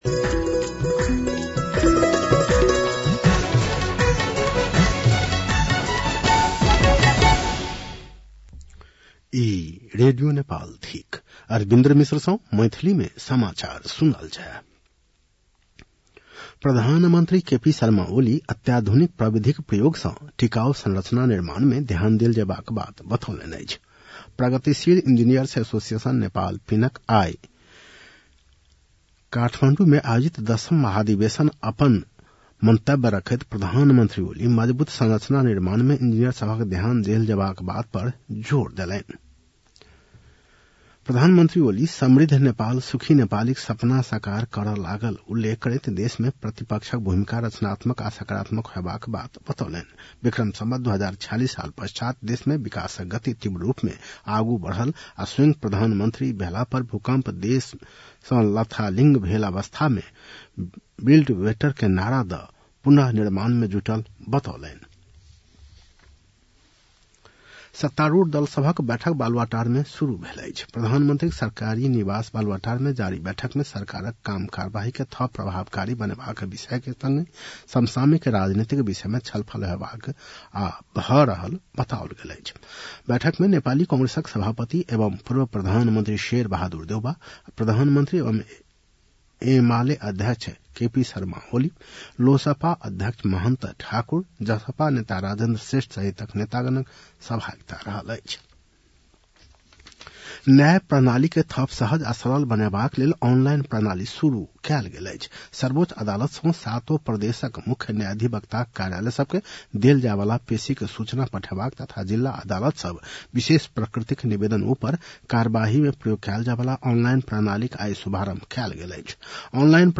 मैथिली भाषामा समाचार : १२ चैत , २०८१